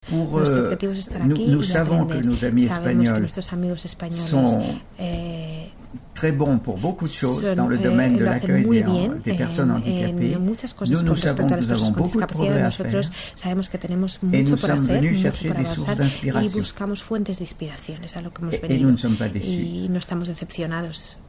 durante su visita al Centro de Recursos Educativos (CRE) de la ONCE en Madrid